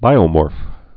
(bīō-môrf)